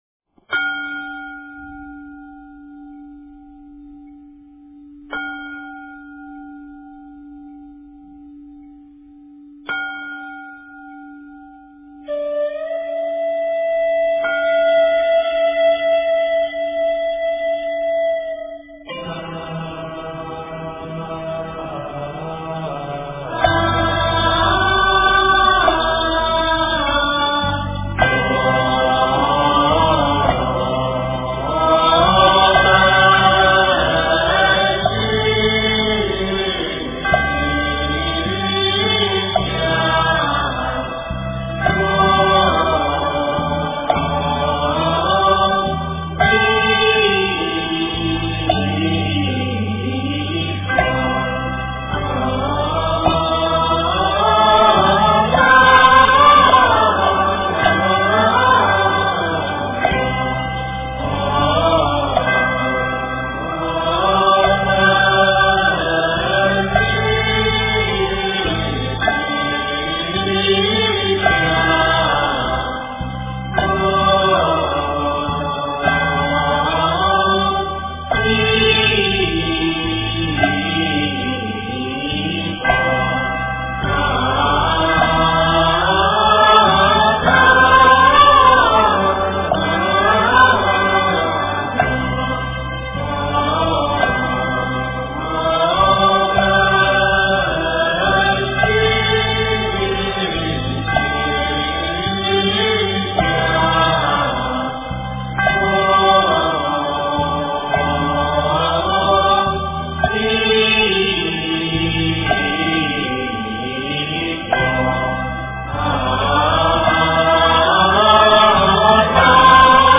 南无本师释迦牟尼佛--拜愿 经忏 南无本师释迦牟尼佛--拜愿 点我： 标签: 佛音 经忏 佛教音乐 返回列表 上一篇： 普贤行愿品--普寿寺 下一篇： 大悲咒.念诵--普寿寺 相关文章 楞严神咒首部曲--毗卢真法会 楞严神咒首部曲--毗卢真法会...